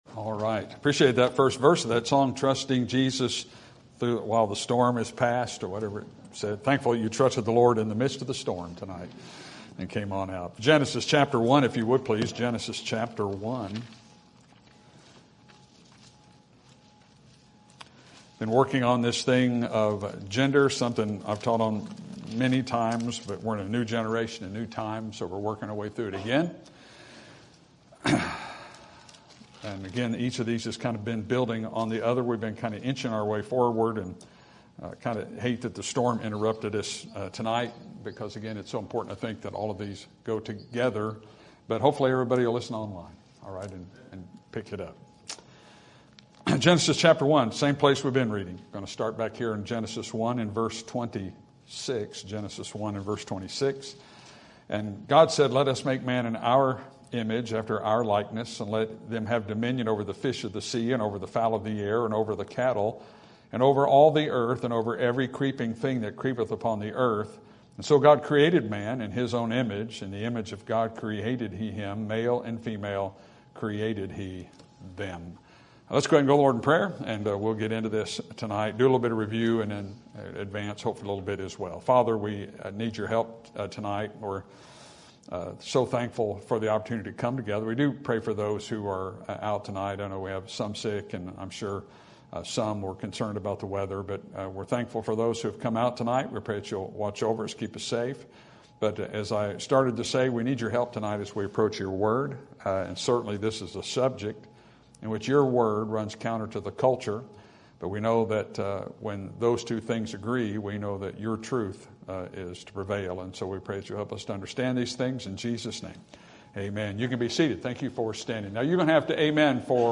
Sermon Topic: Practical Christian Living Sermon Type: Series Sermon Audio: Sermon download: Download (23.7 MB) Sermon Tags: Genesis Doctrine Gender Marriage